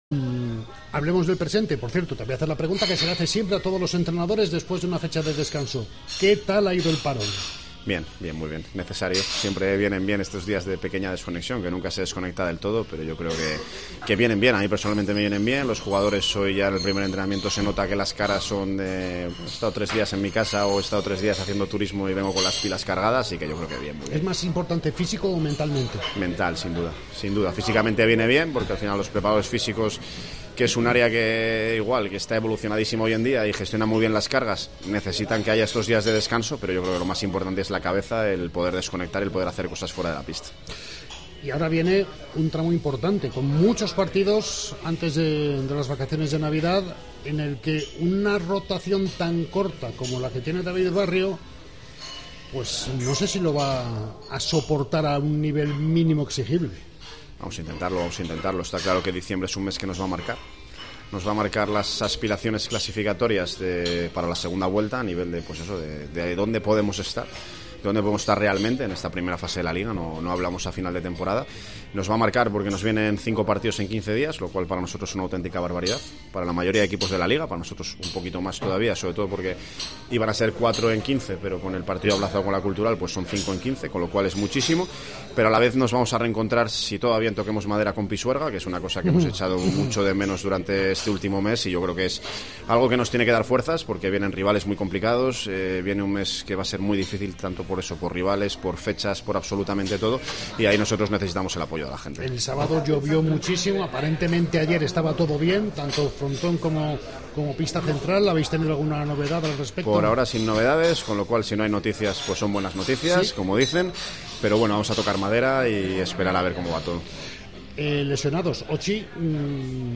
• El entrenador morado ha sido el protagonista del primer programa de diciembre.